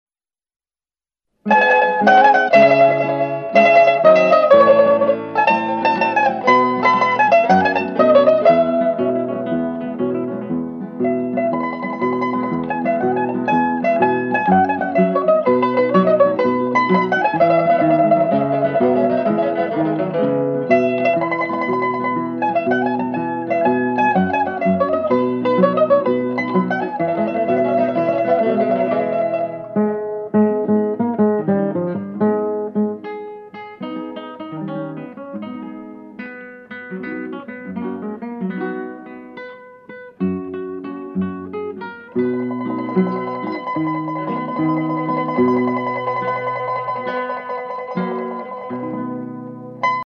• Теги: минусовка